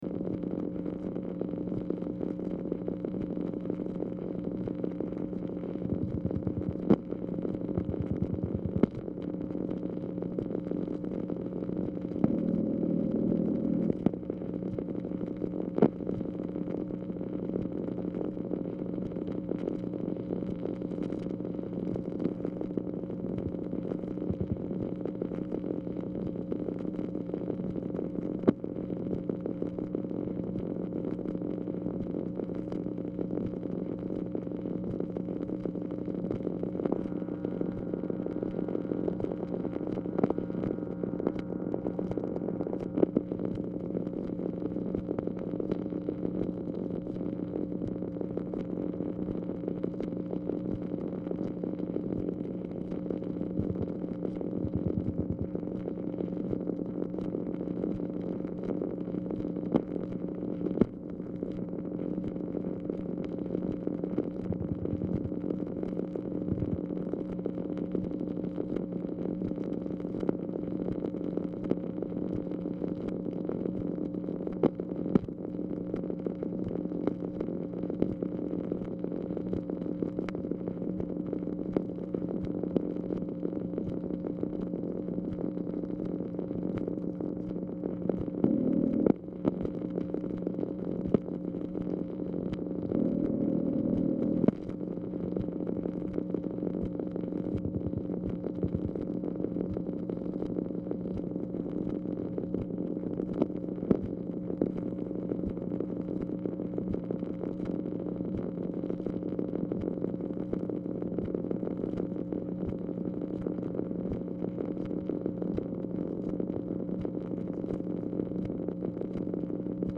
Telephone conversation # 6827, sound recording, MACHINE NOISE, 2/12/1965, time unknown · Discover Production
Telephone conversation # 6827, sound recording
Location of Speaker 1: Mansion, White House, Washington, DC
Format: Dictation belt